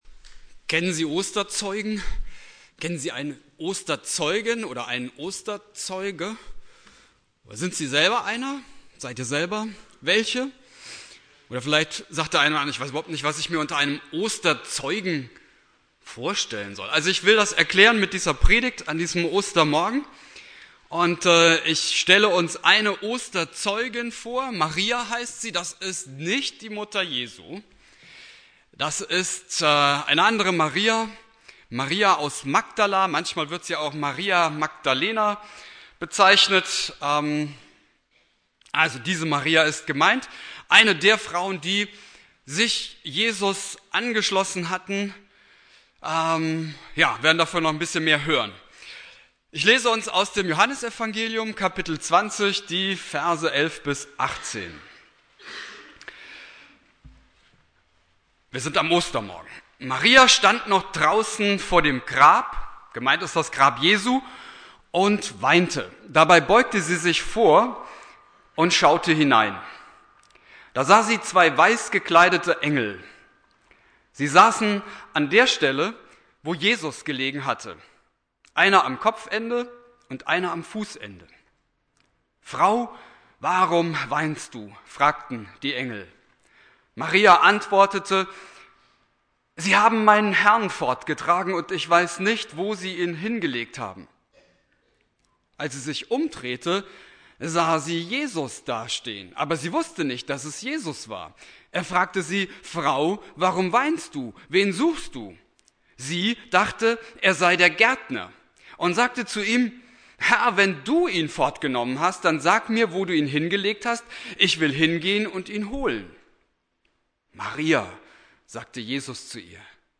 Predigt
Ostersonntag Prediger